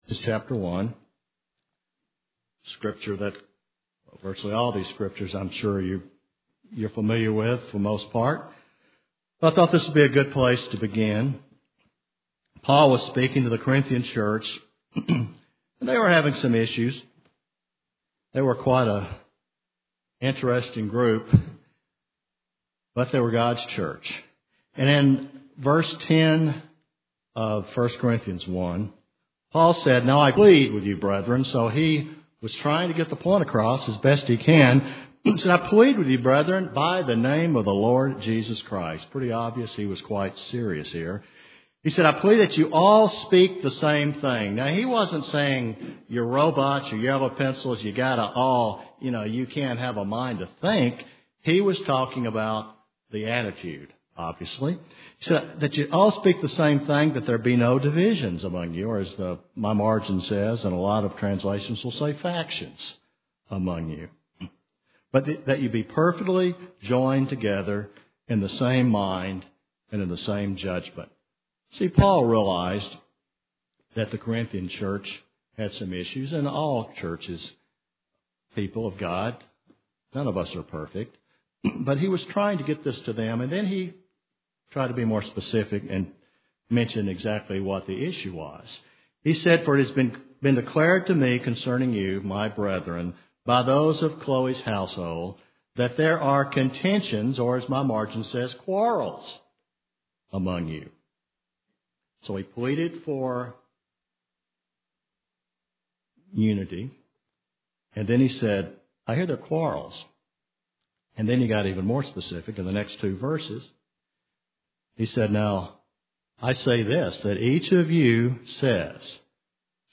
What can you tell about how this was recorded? Given in Nashville, TN